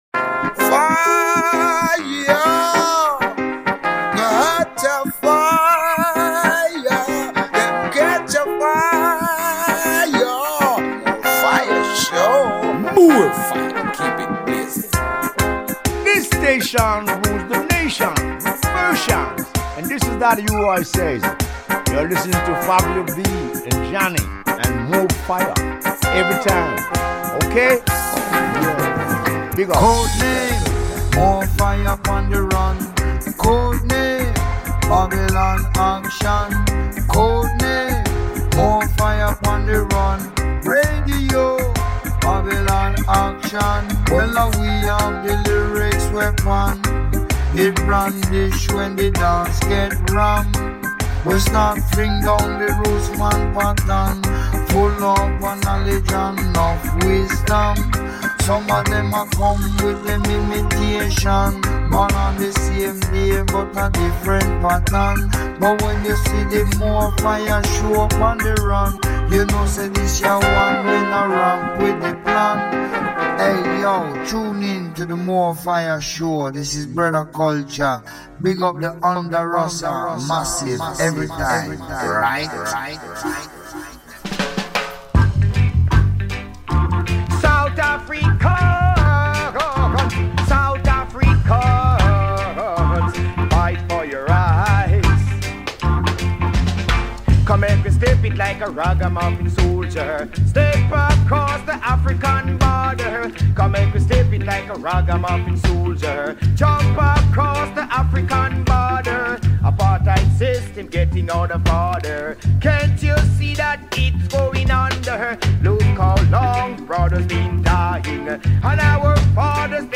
Questa puntata di More Fire ci porta in un viaggio musicale che attraversa epoche e sottogeneri del reggae: dallo ska e rocksteady delle origini fino alle contaminazioni moderne con hip hop, dub, elettronica e soul. Una miscela bilanciata tra classici, rarità e nuove vibrazioni, per esplorare il genere senza restare ancorati al passato, e sempre con uno sguardo rivolto al messaggio.